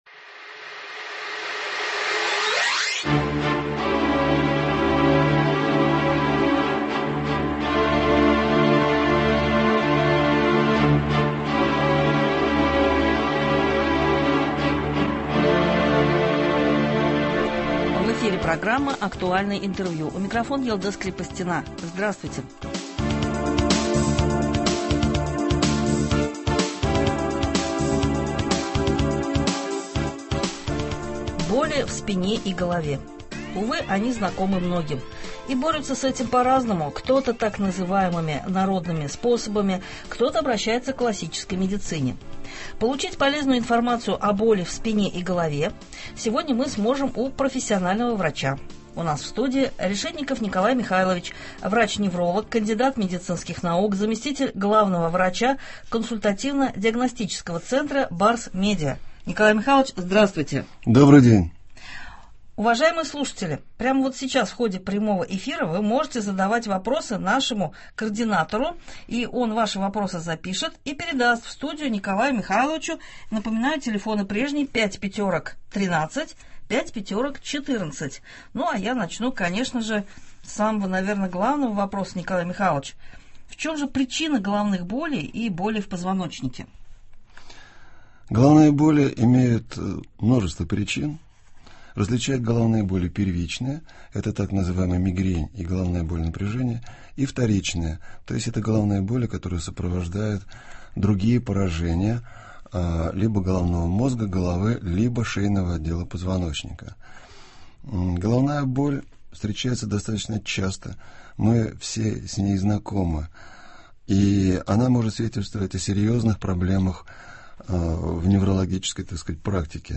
Актуальное интервью (15.03.23) | Вести Татарстан